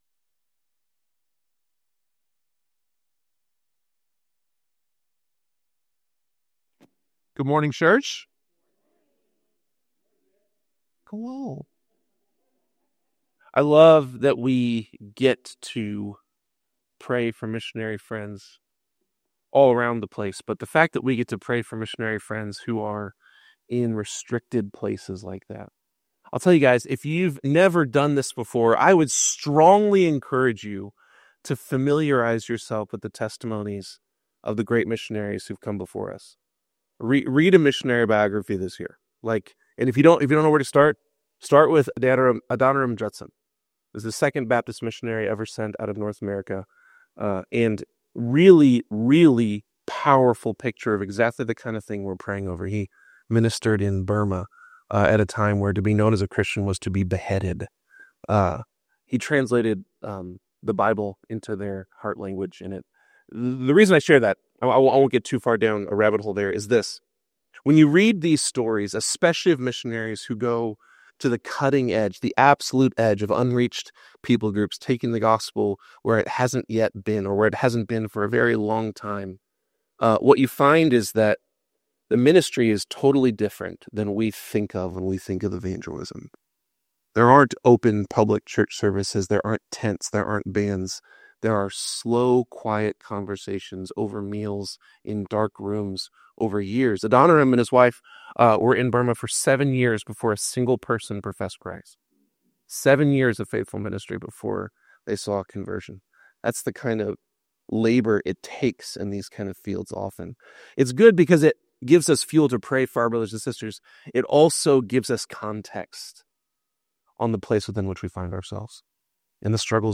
Whether you're new to faith or a long-time believer, this sermon invites you to drink deeply from the well of Christ's love and grace.